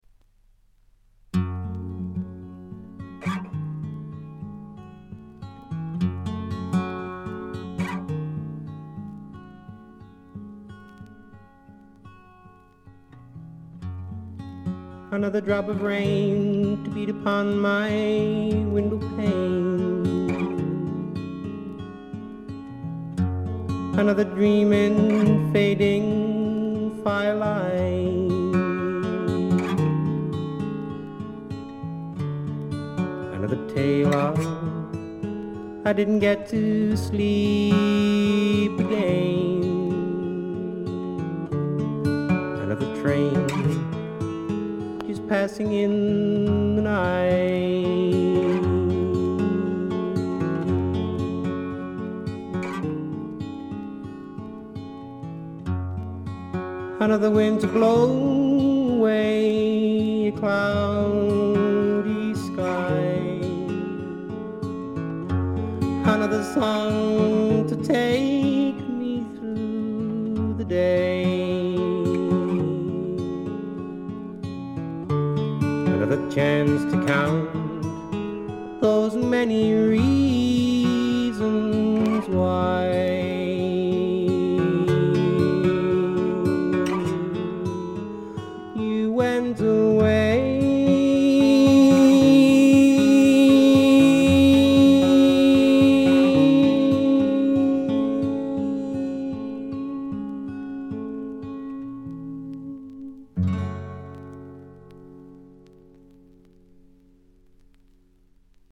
部分試聴ですが、軽微なノイズ感のみ。
やさしさにあふれた英国シンガー・ソングライターの好盤です。
試聴曲は現品からの取り込み音源です。
Recorded At - Morgan Studios